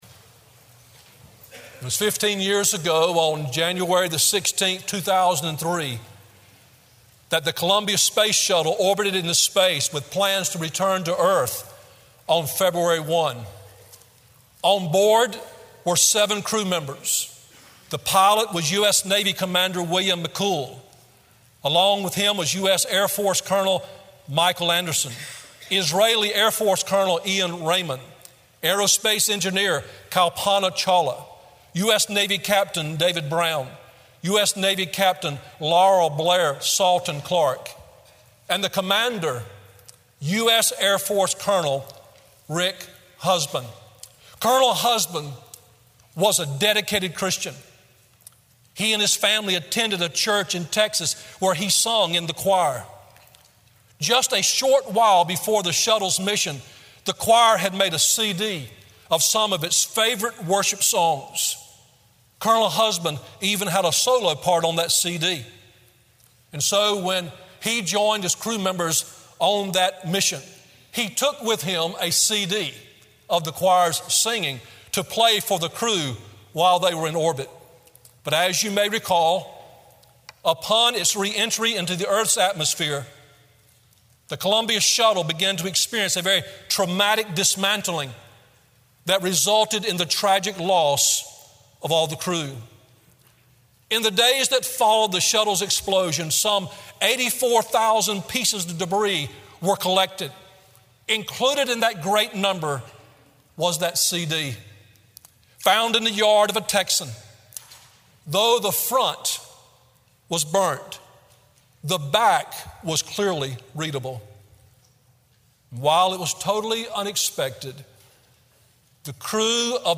Sermon Audios/Videos - Tar Landing Baptist Church
Morning Worship2nd Corinthians 5:14-21